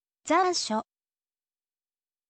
zansho